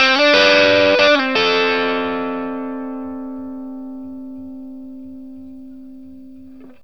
BLUESY1 D 60.wav